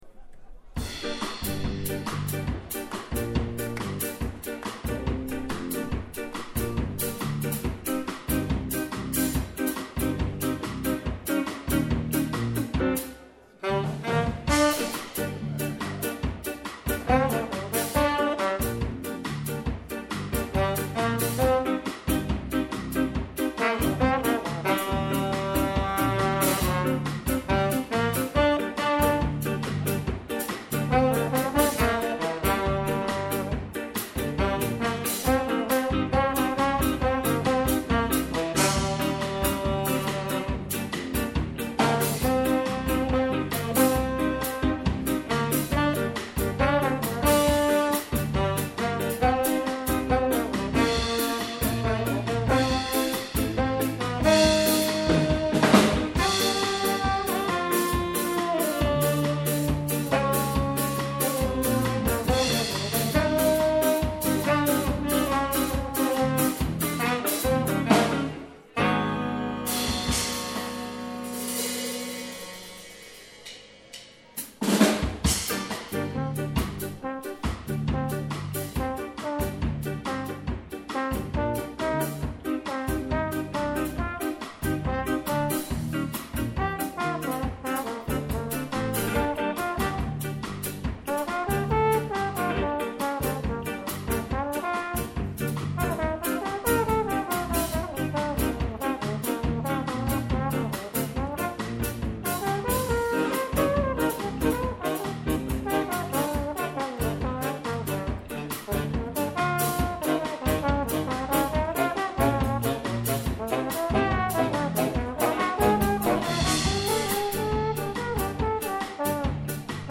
live in der Sandgrube 25 in Speikern am 16.7.2022
saxophone
trombone
guitar
bass
drums